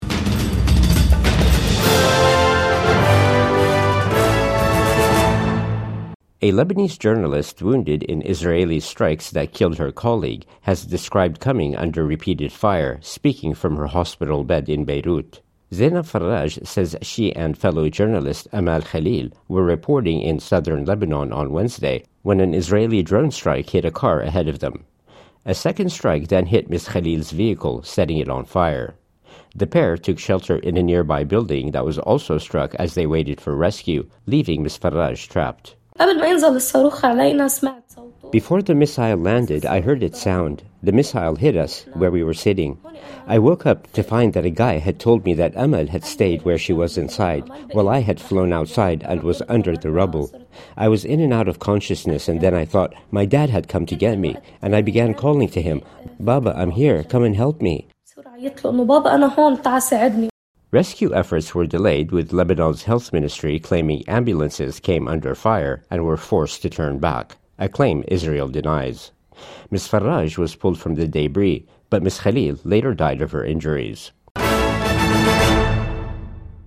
Wounded Lebanese journalist recounts deadly Israeli strikes